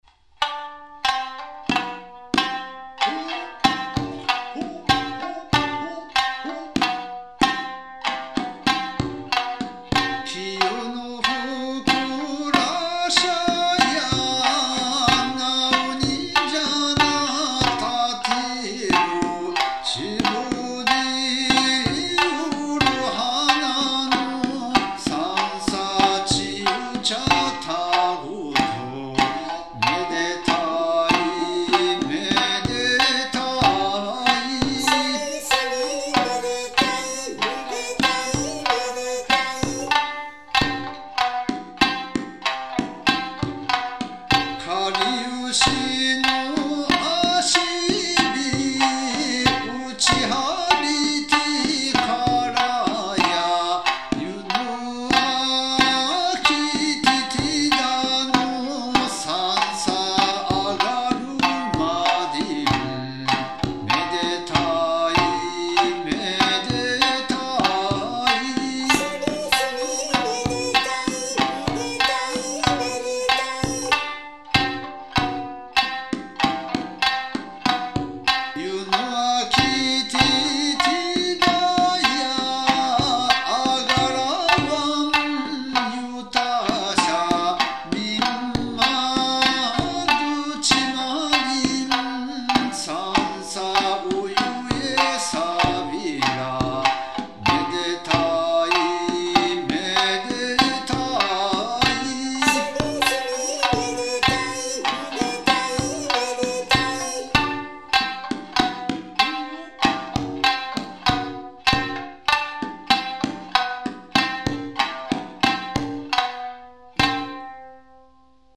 明るく軽快。
男女掛け合いで歌う。
歌三線
三線　笛　太鼓　三板